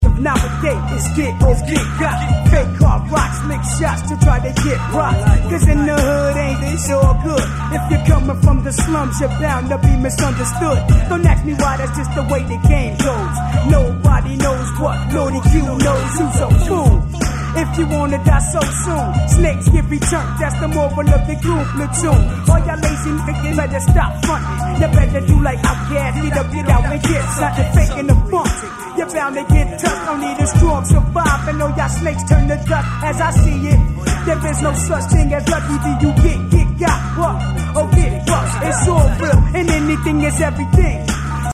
gangsta/hardcore hip-hop